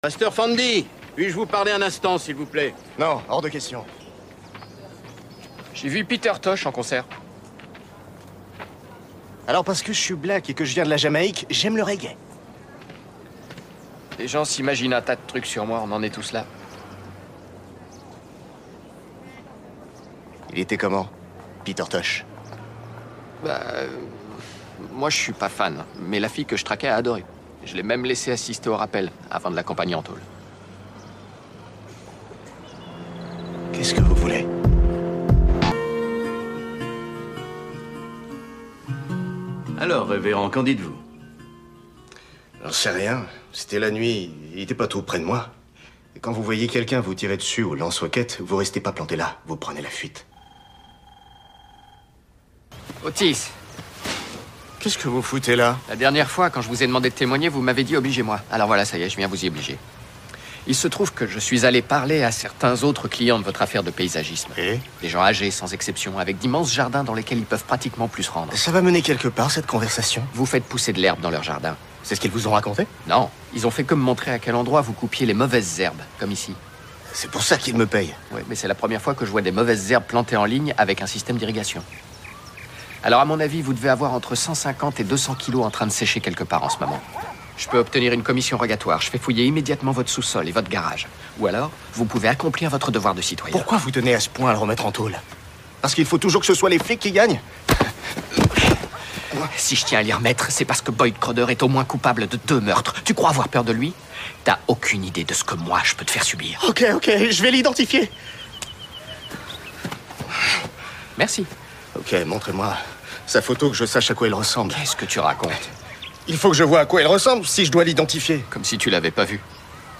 Doublage de Justified : prêtre arnaqueur apeuré.
Diffusé sur Paris Première.